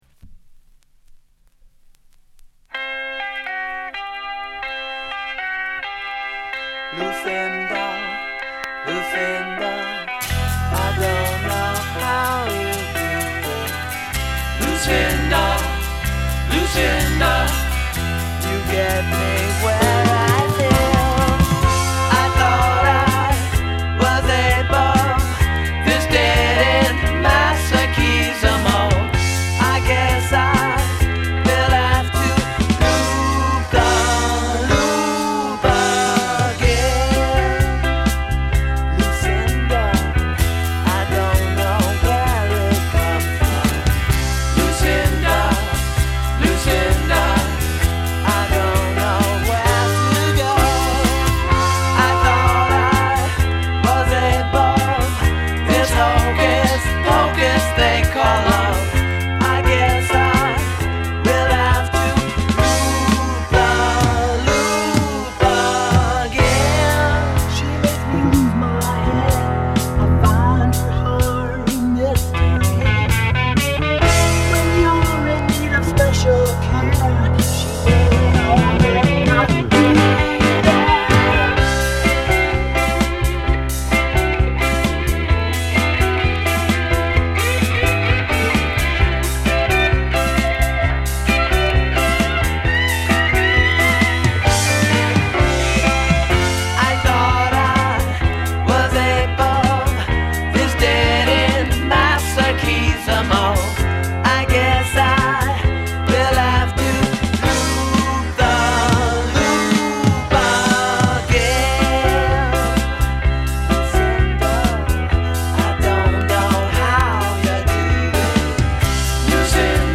jaunty pop music
piano
rock-meets-Vaudeville tunes